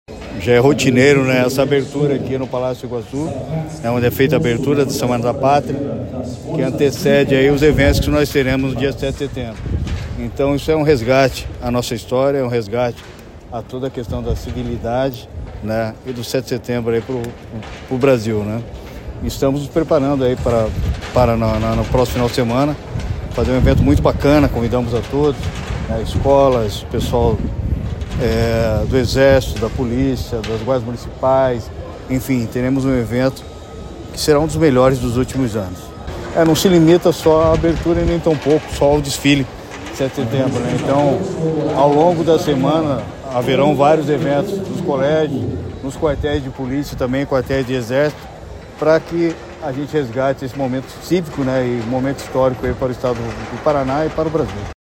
Sonora do secretário da Segurança Pública, Hudson Teixeira, sobre o início da Semana da Pátria